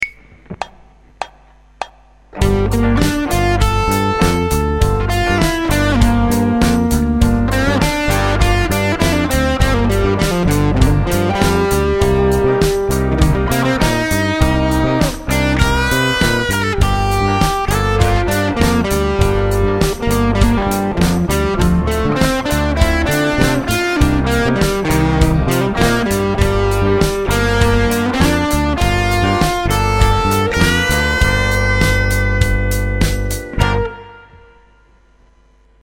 The next element to be added to the guitar solo will be the use of 9th chord arpeggios giving it a jazzy sound.
The solo example below uses the arpeggios and modes but also includes phrasing by playing longer and shorter notes to create a more melodic solo.
Finally note how guitar techniques such as bends, hammer-ons, pull-offs and slides are used to create expression in the solo.
minorblues_solo.mp3